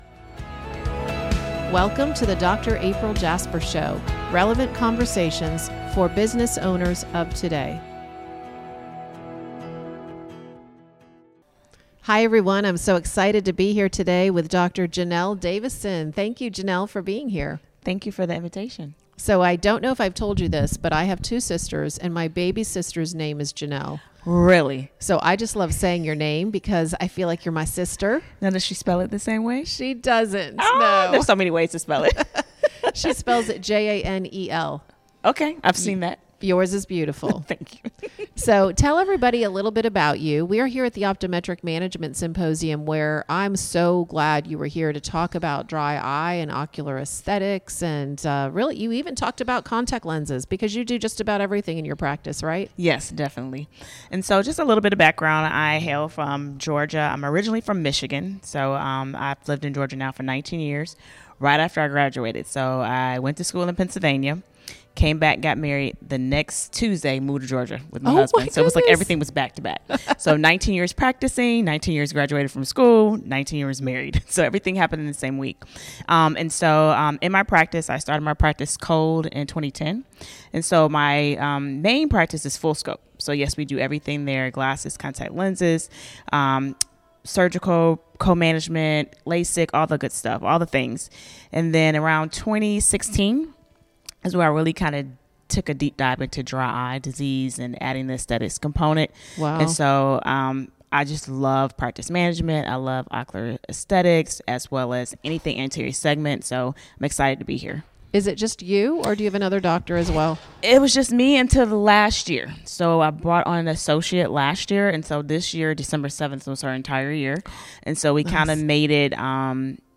recorded live at HIMSS25 in Las Vegas